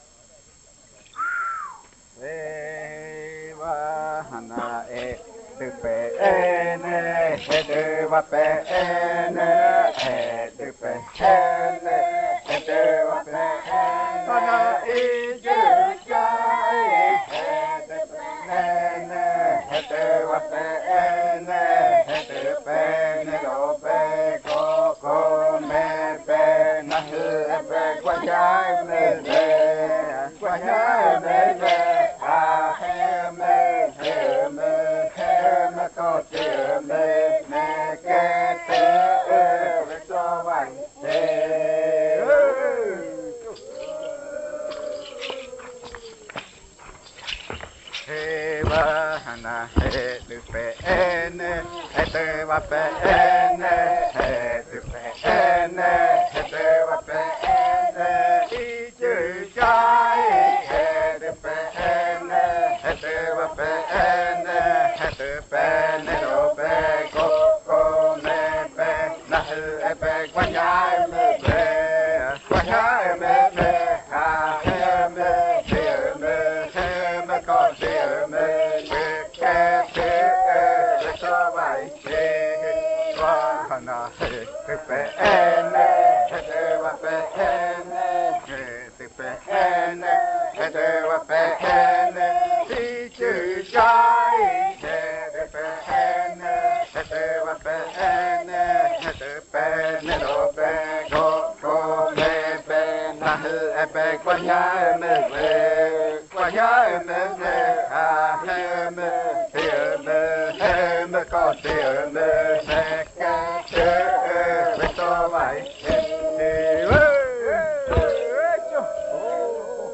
42. Baile de nombramiento. Canto n°6
Puerto Remanso del Tigre, departamento de Amazonas, Colombia